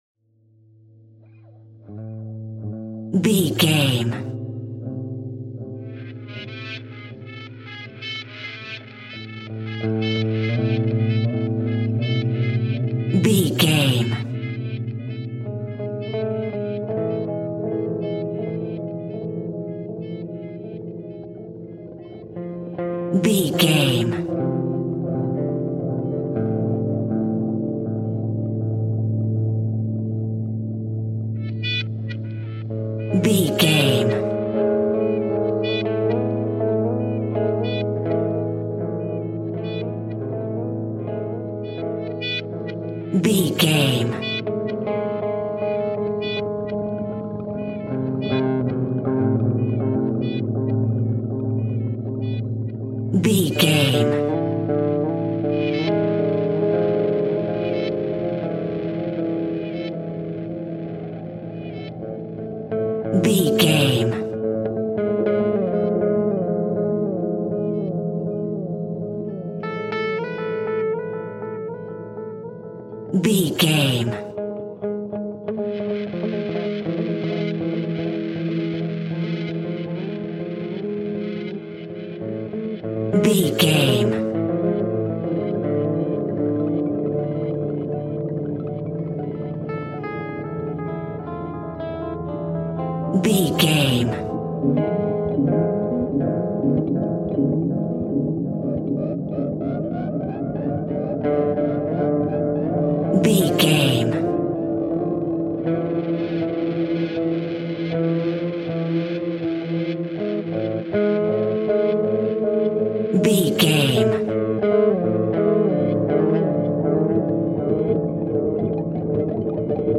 Survival horror
Diminished